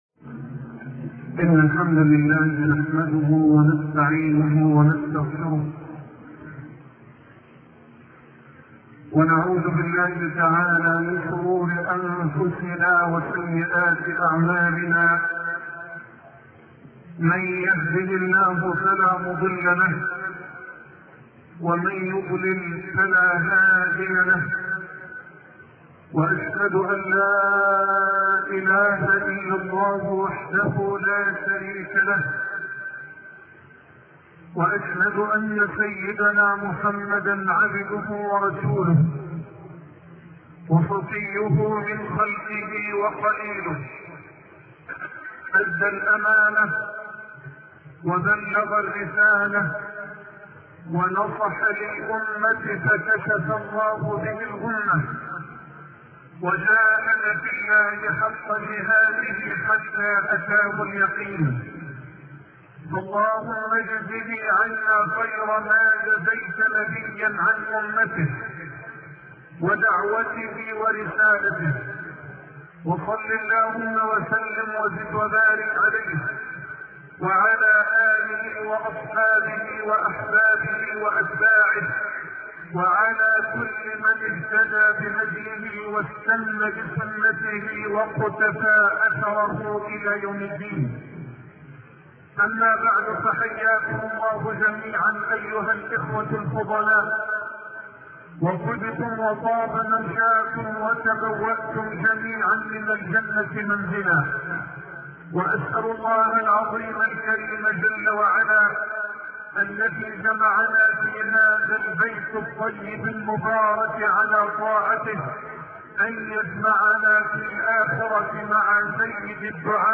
شبكة المعرفة الإسلامية | الدروس | الحرب على الثوابت [1] |محمد حسان